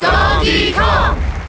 Donkey Kong's Melee Crowd Chant (NTSC) You cannot overwrite this file.
Donkey_Kong_Cheer_SSBM.ogg